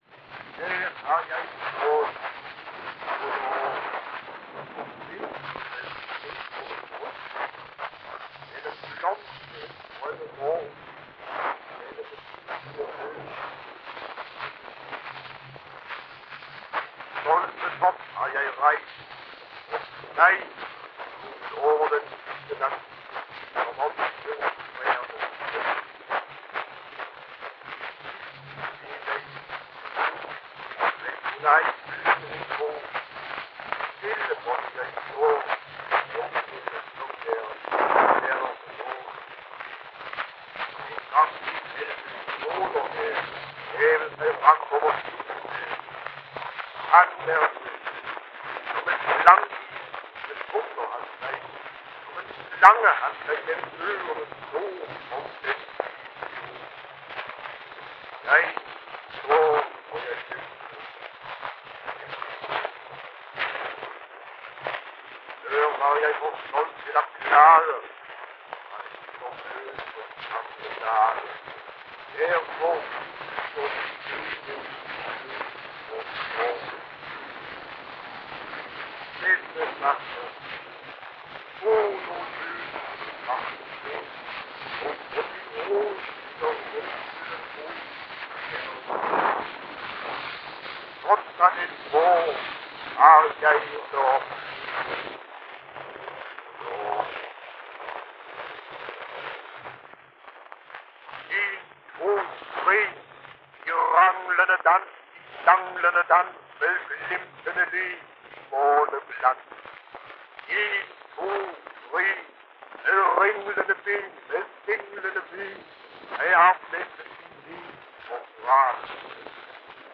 To vers-monologer. Første monolog er komplet. I anden monolog er 4. vers ud af i alt 5 vers udeladt.
Rubenvalse